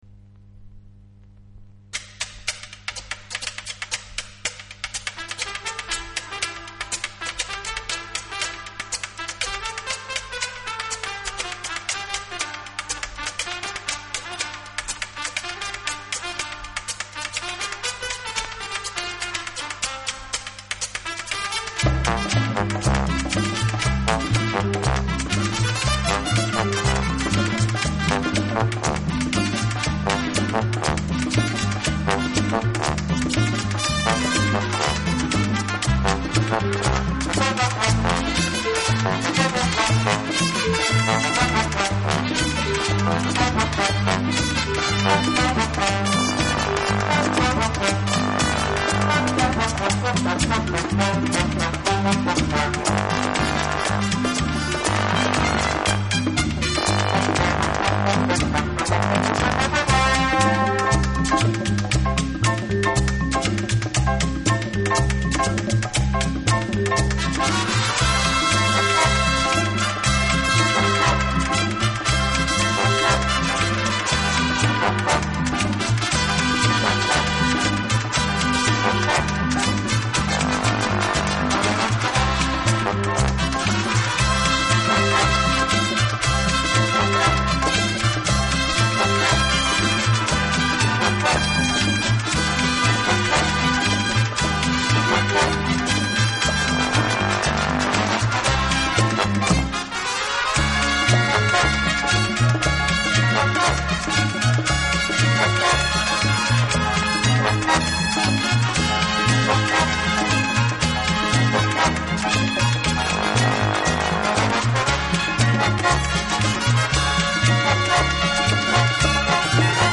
【轻音乐专辑】
以擅长演奏拉丁美洲音乐而著称。